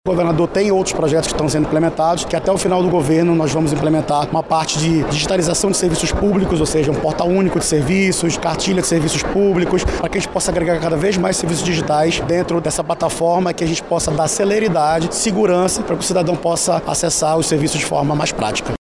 O titular da Sead, Fabrício Barbosa, afirmou que o Governo do Estado vai digitalizar e tornar mais agil e eficaz outros serviços, até o fim do mandato.